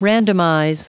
Prononciation du mot randomize en anglais (fichier audio)
Prononciation du mot : randomize